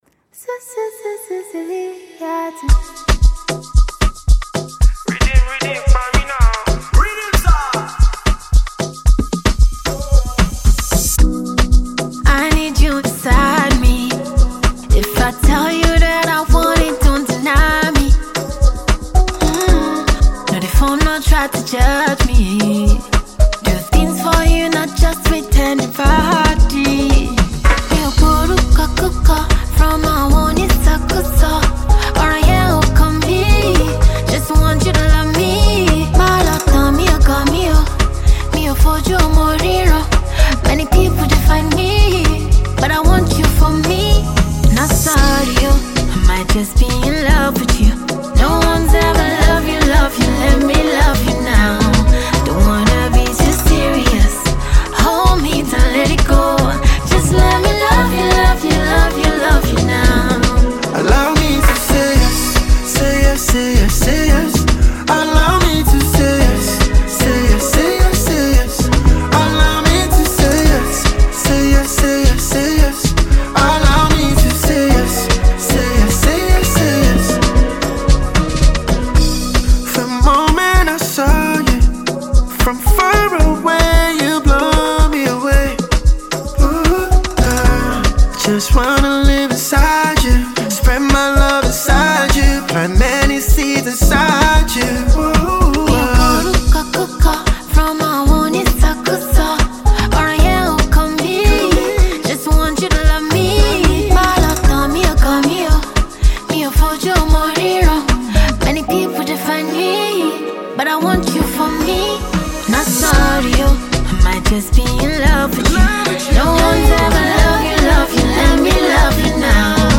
Versatile fast-rising Nigerian Female singer